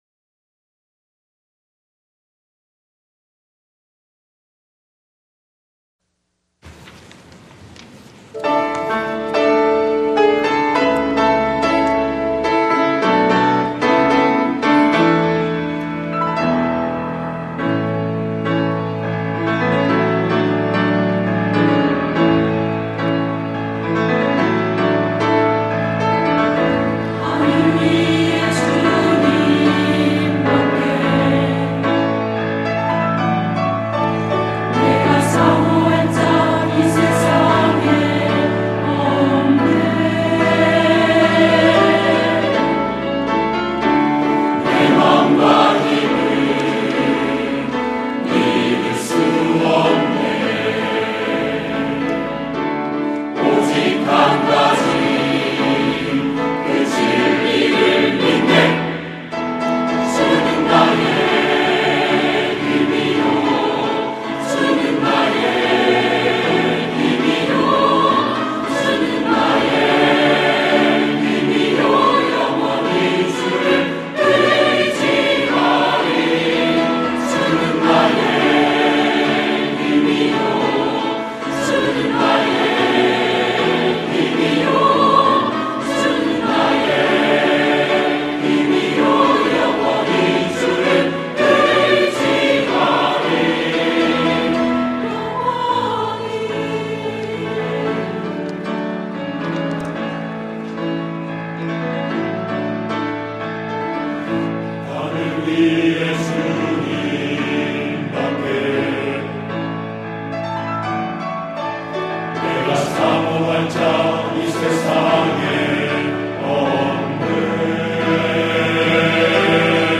하늘 위에 주님 밖에 > 찬양영상